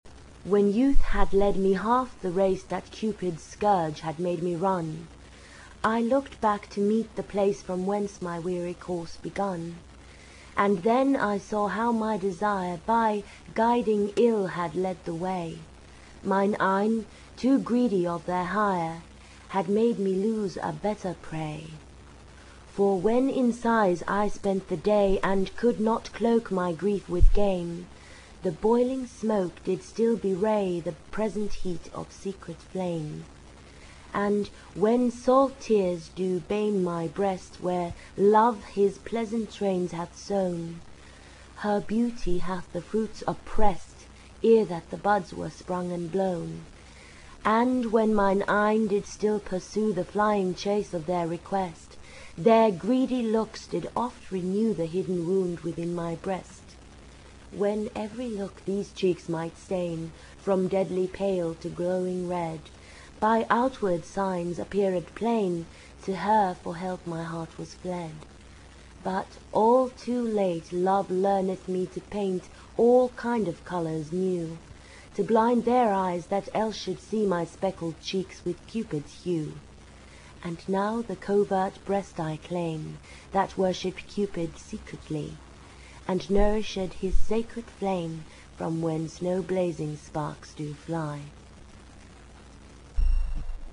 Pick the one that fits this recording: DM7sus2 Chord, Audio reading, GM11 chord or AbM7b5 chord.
Audio reading